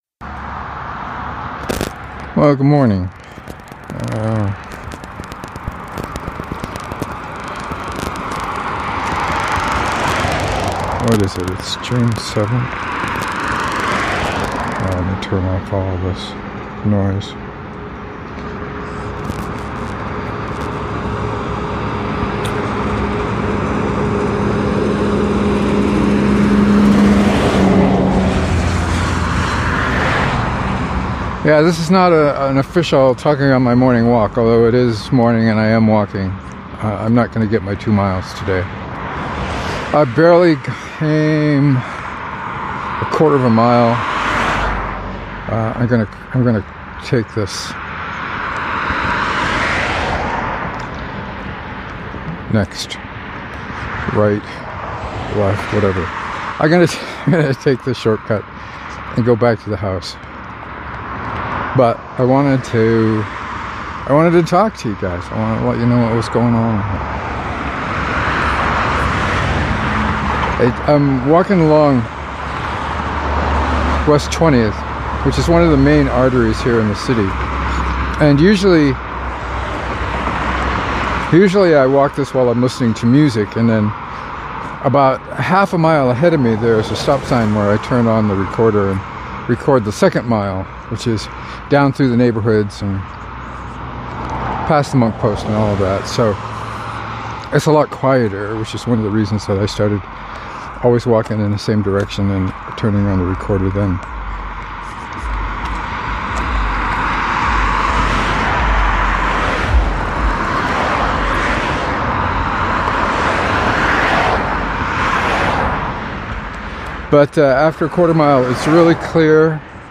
I soon realized that I wasn’t going to make it so started a quick update before taking the short-cut back to the house.